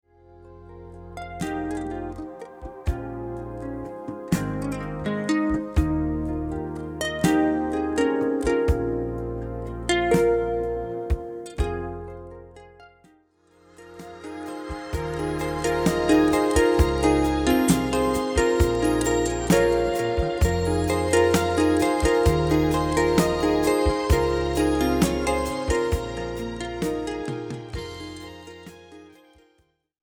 electroacoustic pedal harp, gu-cheng & more...
Recorded and mixed at the Sinus Studios, Bern, Switzerland